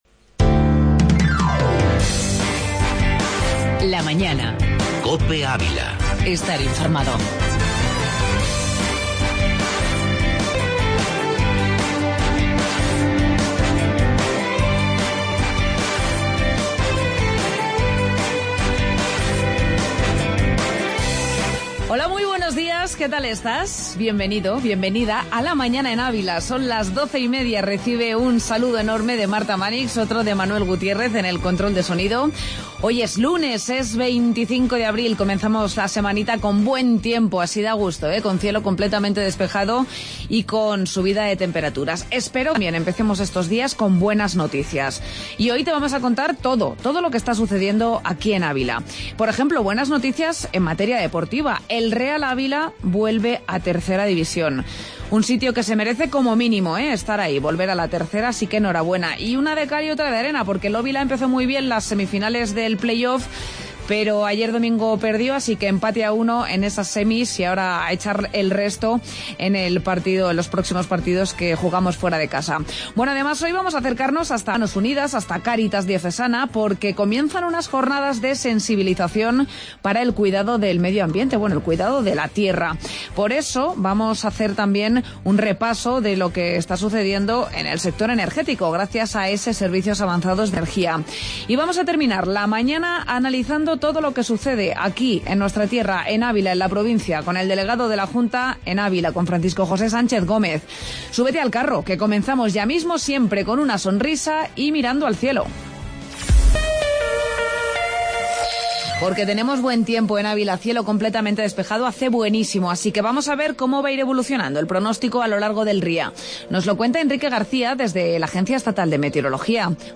AUDIO: Espacio ESSE y Entrevista Cáritas